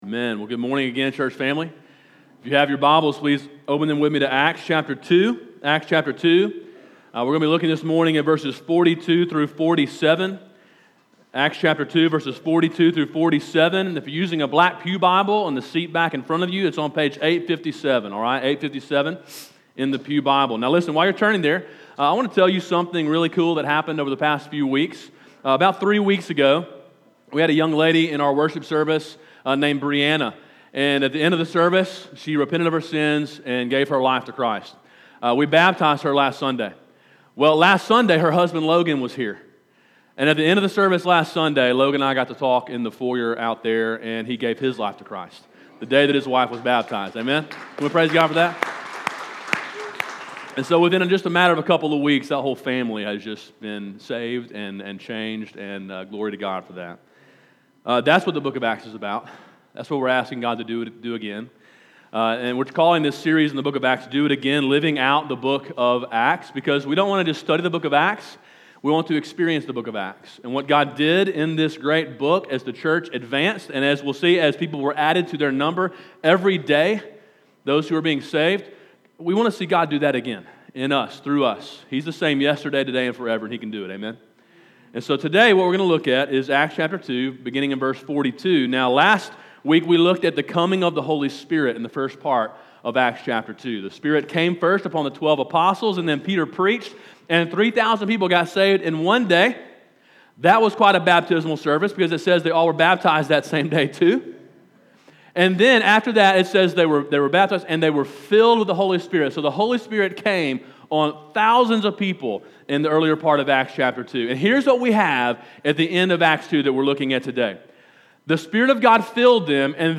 Sermon: “The Church’s Vital Signs” (Acts 2:42-47)